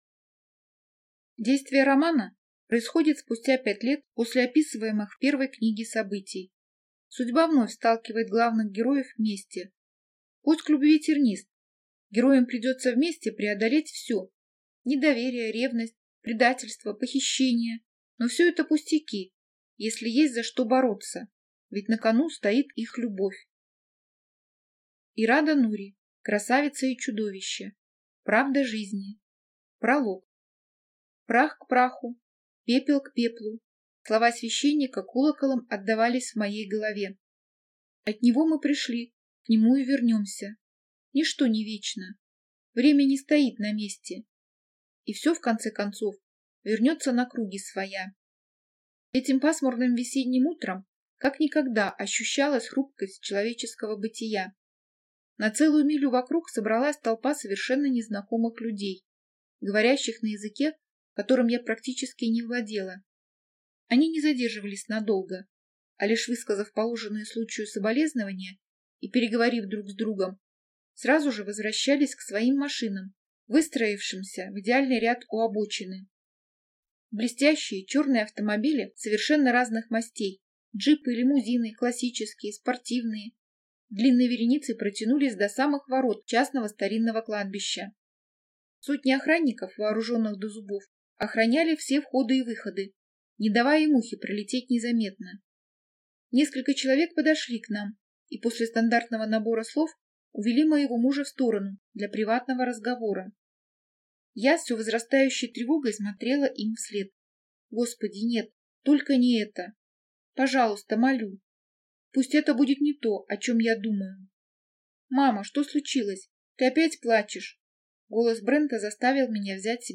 Аудиокнига Красавица и чудовище. Правда жизни | Библиотека аудиокниг